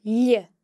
LearnGaelic - Gaelic sounds – Pronounce the slender l
The slender L sound is made by pressing your tongue against the roof of your mouth (palate) while pronouncing the letter, and occurs when the L is before or after an e or i. The slender L can be heard in leugh (read):